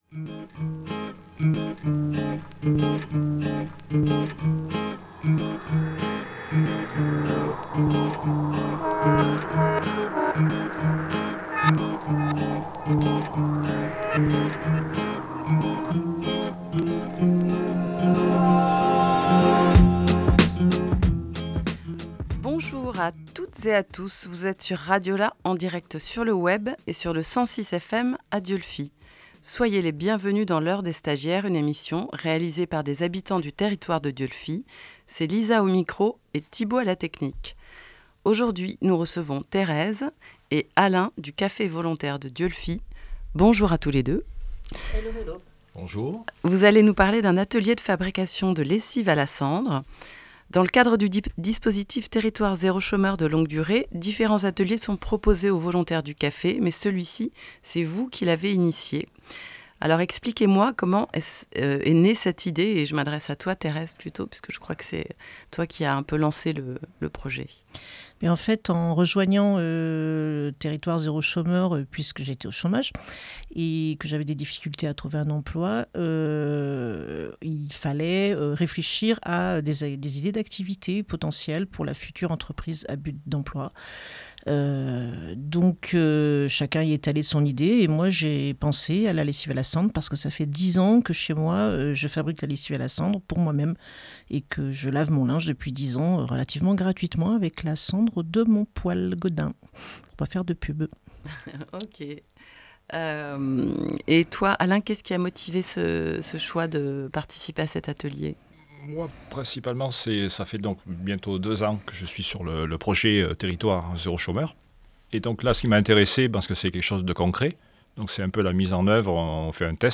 25 juin 2024 16:43 | Dessine-moi une radio, Interview
Bienvenue dans L’Heure des stagiaires, une émission réalisée par des habitant-es du territoire de Dieulefit Bourdeaux.